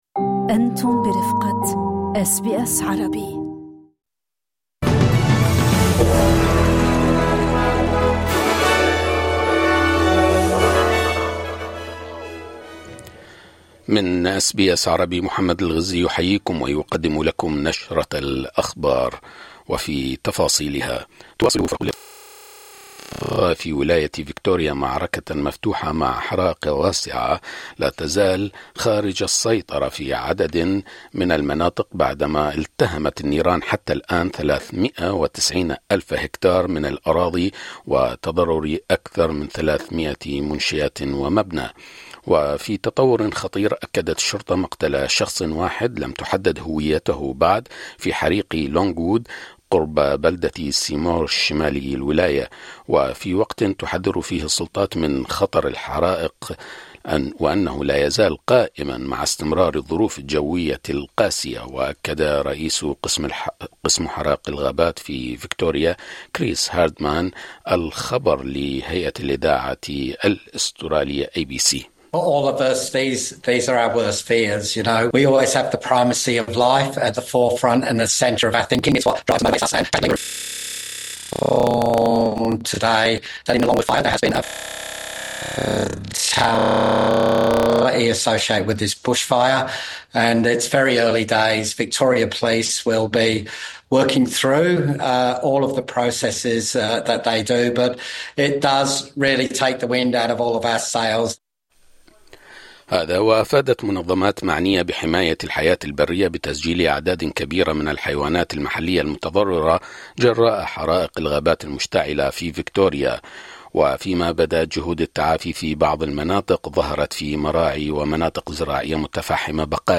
نشرة أخبار الظهيرة 12/01/2025